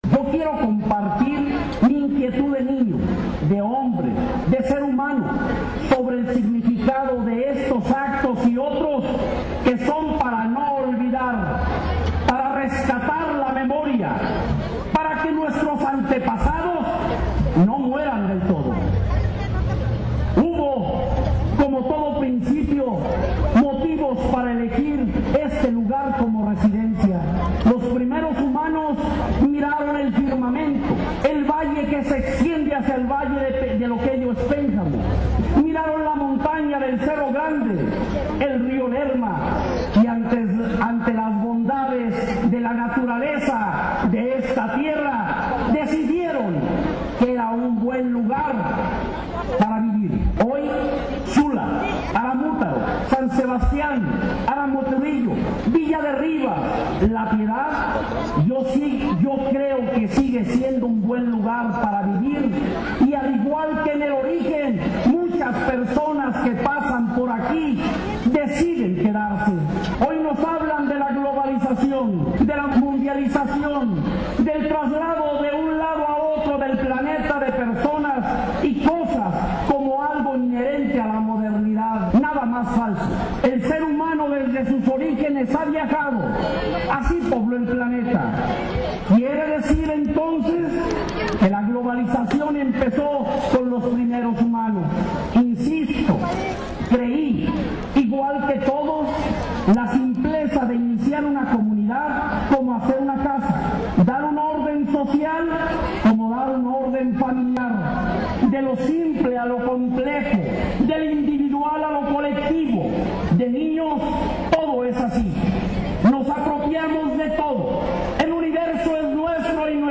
Autoridades municipales y escolares docentes y administrativos, así como alumnos del nivel primario de diferentes instituciones y sociedad civil, encabezadas por el alcalde Juan Manuel Estrada Medina, fueron partícipes del acto cívico en la explanada del centro histórico para conmemorar el 486 aniversario del avecindamiento de La Piedad.